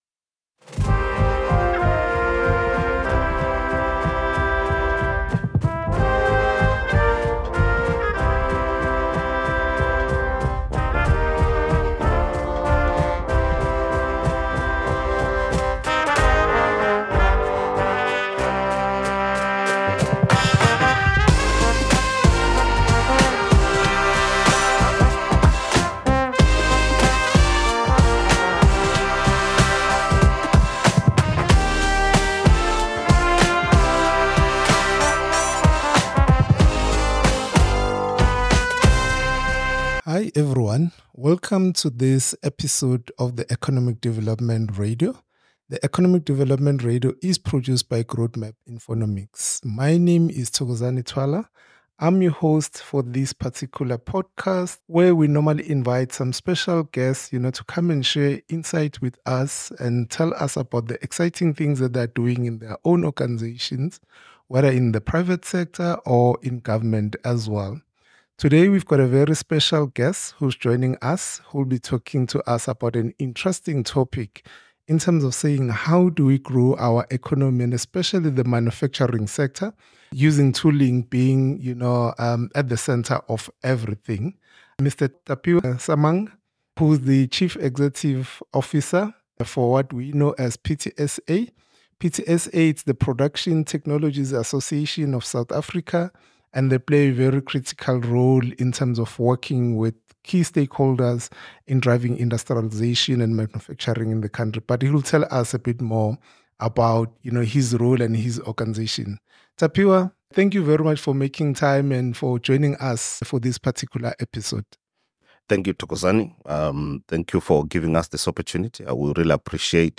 The Economic Development Radio has been created as a platform to bring you interviews and discussions via podcasts and articles with:Economic Development professionals, talking about issues facing the industry and their provinces, cities and towns.